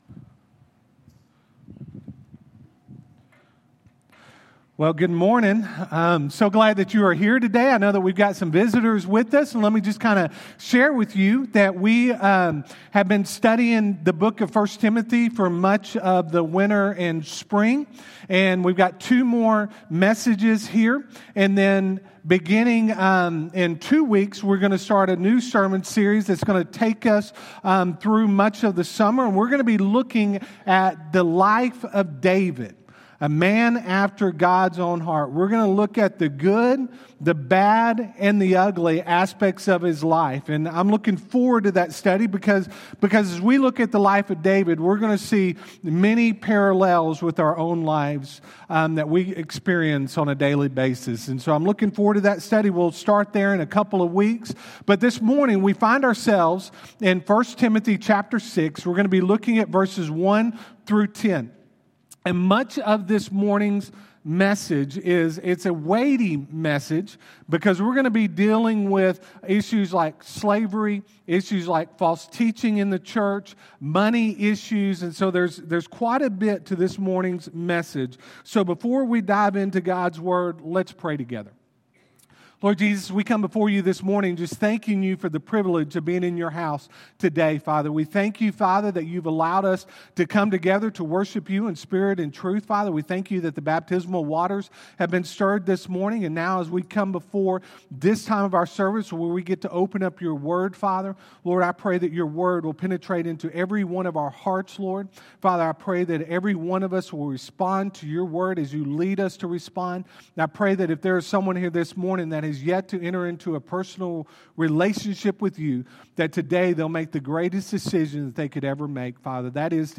Sermons
Friendship Baptist Church SERMONS